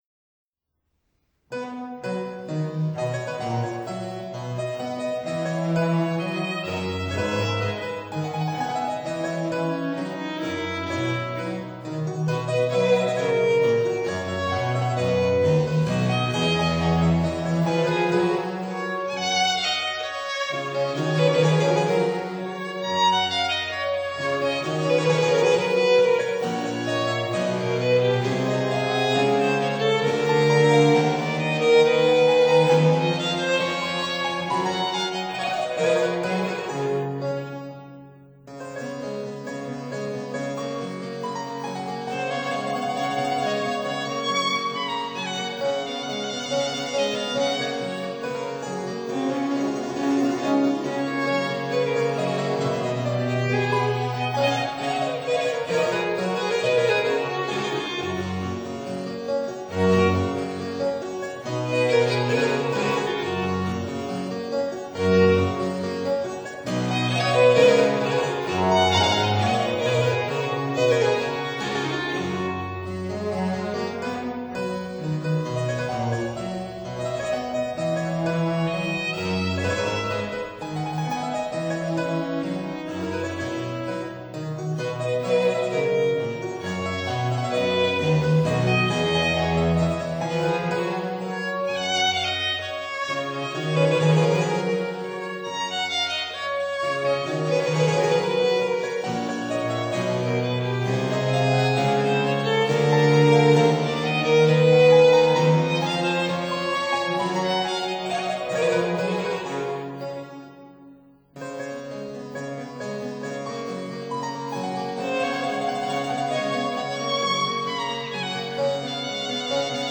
Six Sonatas for Harpsichord, Violin & Cello, Op. 2 (1760)
(Period Instruments)